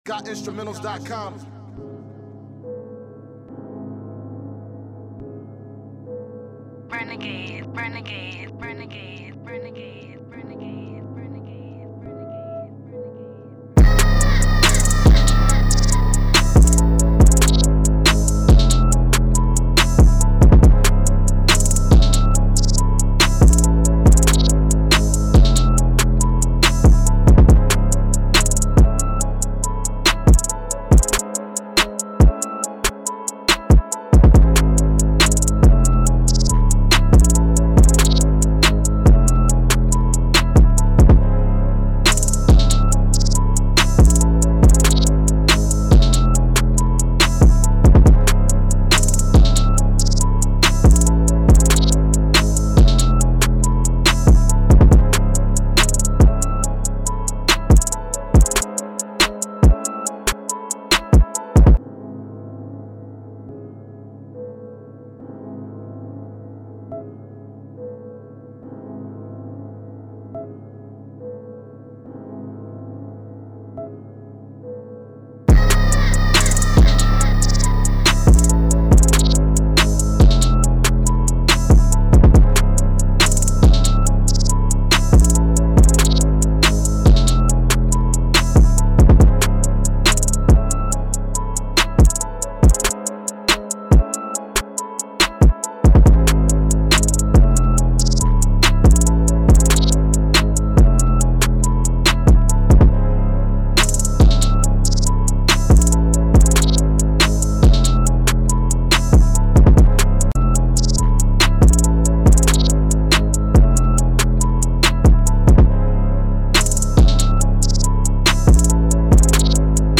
Sick instrumentals on this tape